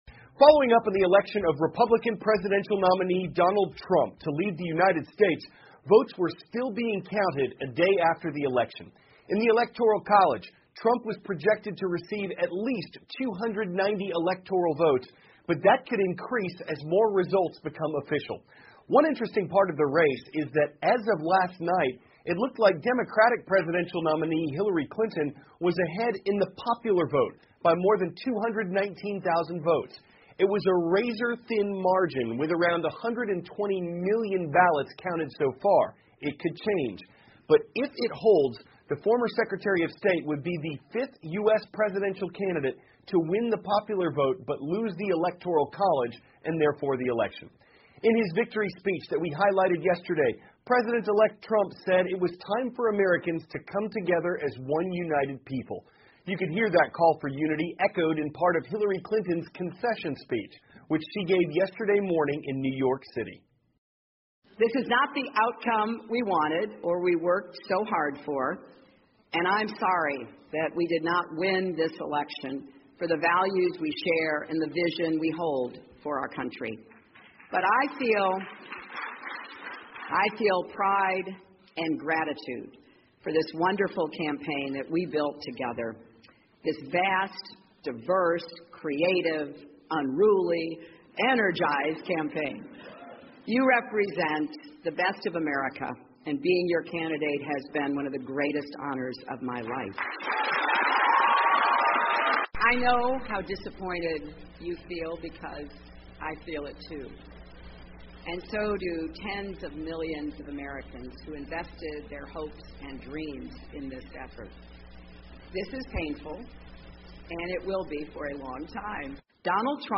美国有线新闻 CNN 希拉里赢了普选输了白宫 败选演讲呼吁团结 听力文件下载—在线英语听力室